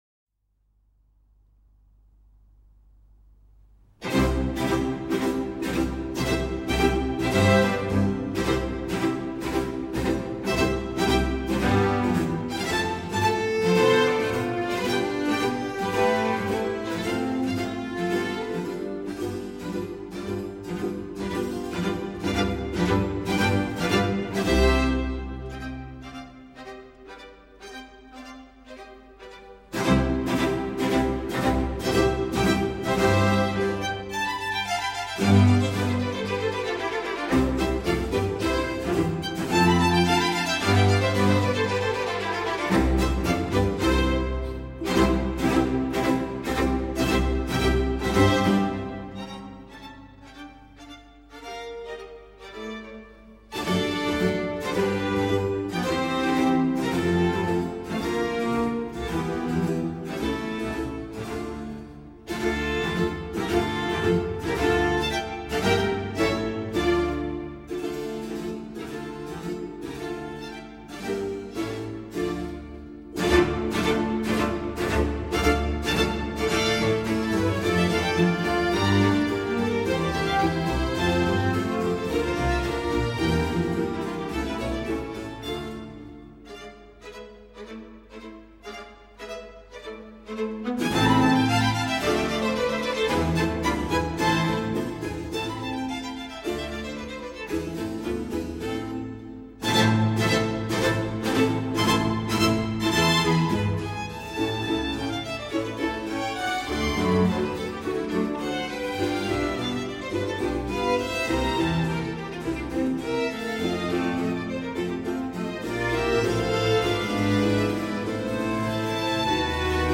Concerto
for strings & continuo in C